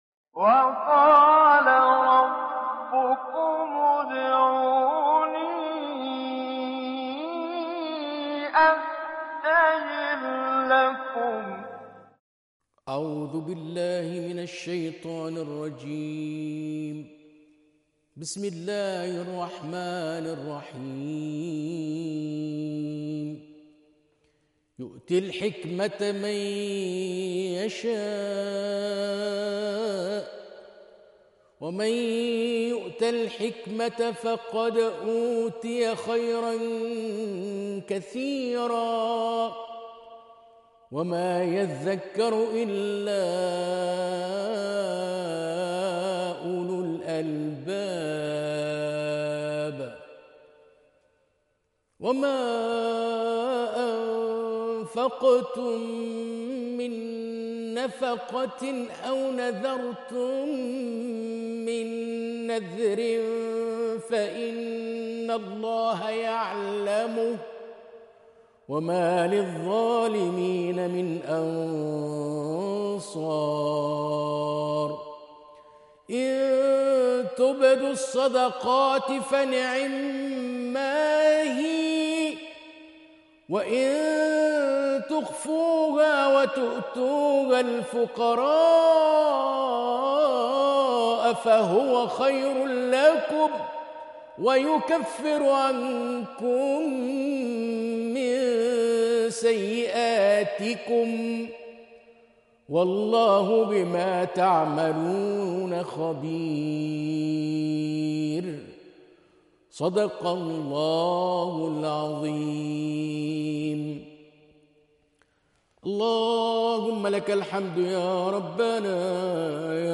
الزهد والرقائق     الذكر والدعاء